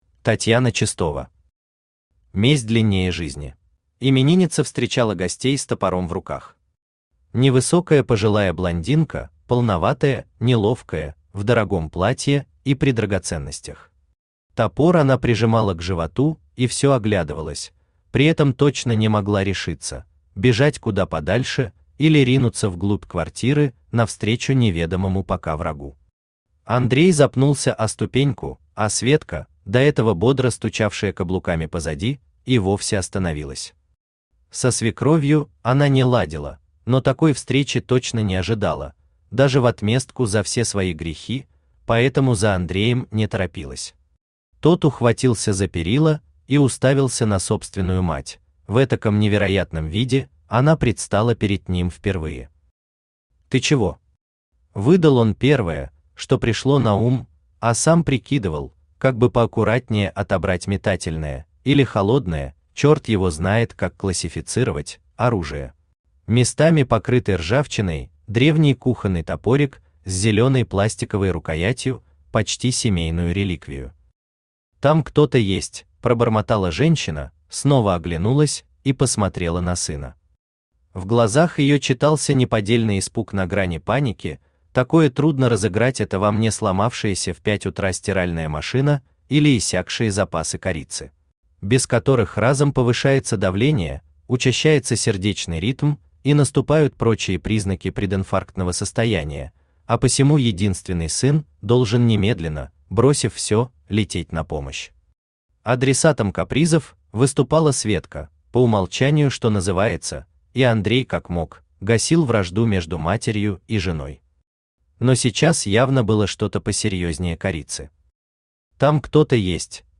Аудиокнига Месть длиннее жизни | Библиотека аудиокниг
Aудиокнига Месть длиннее жизни Автор Татьяна Чистова Читает аудиокнигу Авточтец ЛитРес.